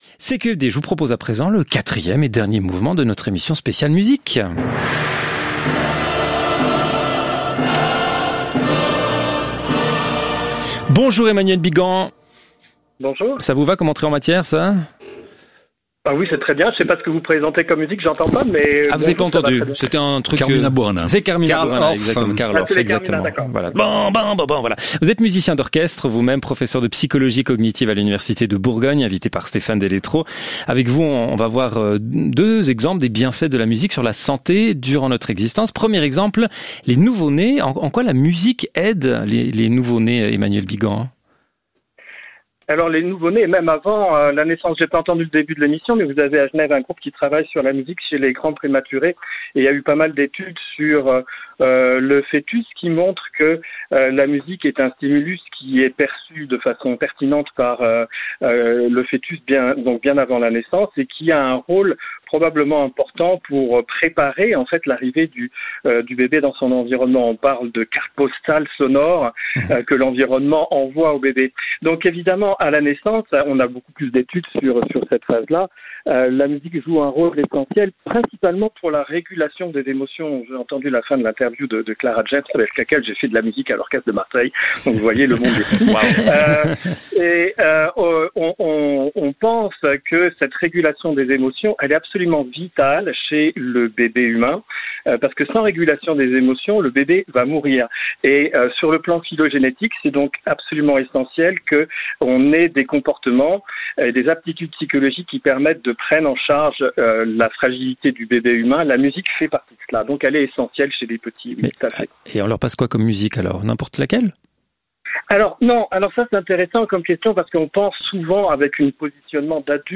A l’occasion de la fête de la musique, les équipes de CQFD ont consacré leur intervention du 21 juin aux bienfaits de la musique sur le cerveau. Pour cette émission diffusée sur RTS, les journalistes ont notamment invité 2 scientifiques reconnus :